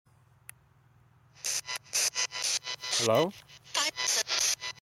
During the Spirit Box session, an unexplained female voice says HI in response to me saying Hello.